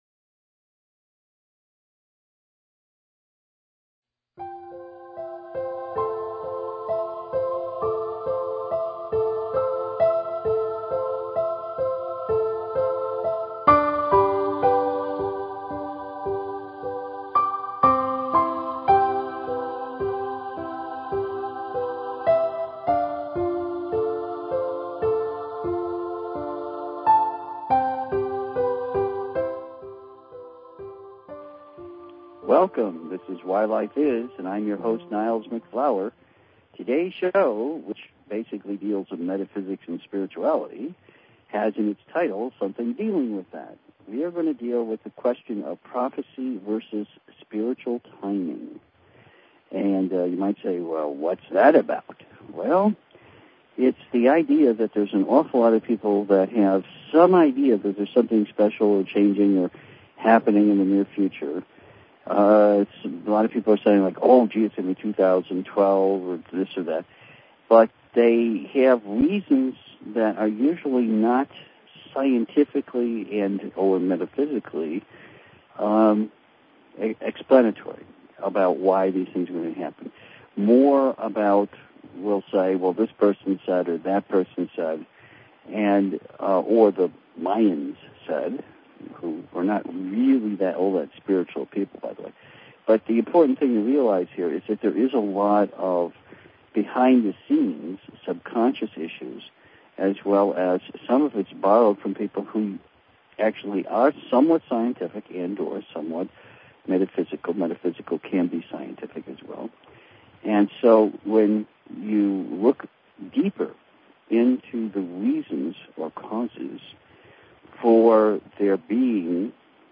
Talk Show Episode, Audio Podcast, Why_Life_Is and Courtesy of BBS Radio on , show guests , about , categorized as